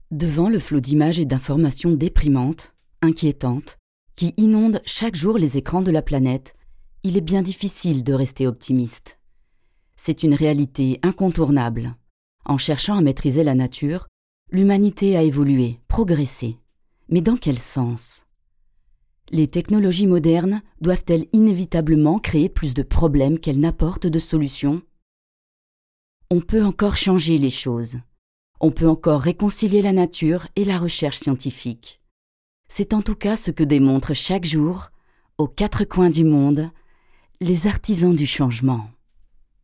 DOCUMENTAIRE